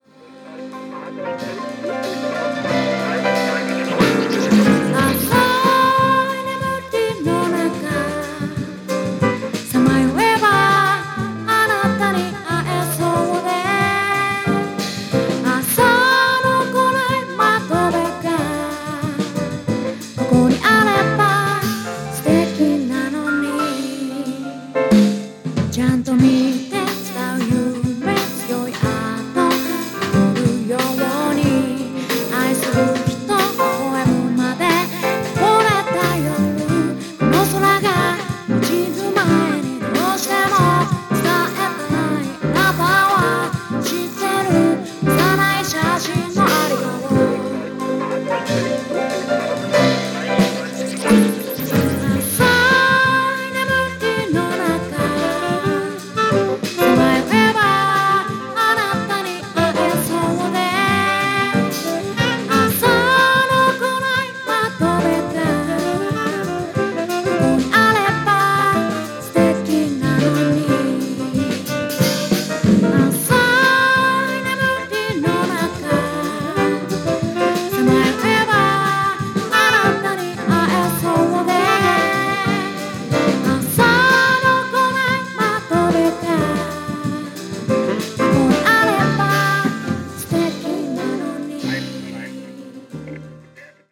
癒されますね～。